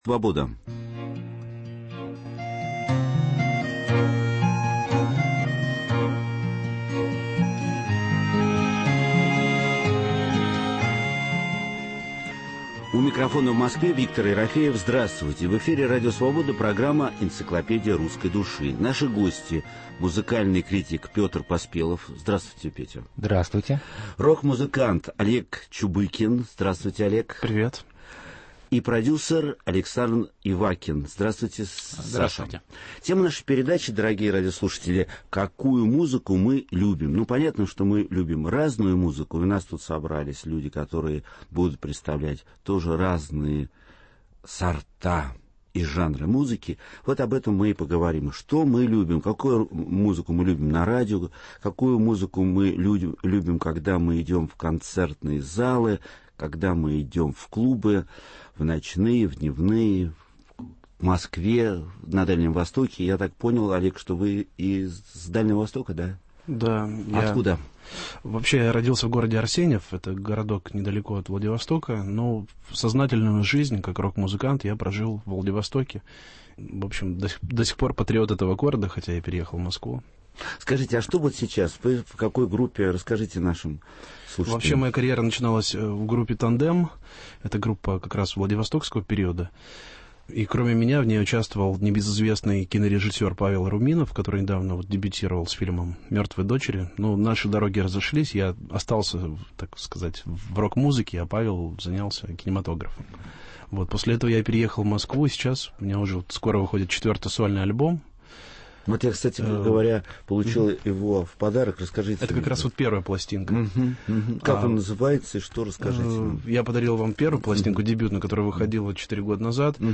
Гости – музыкальный критик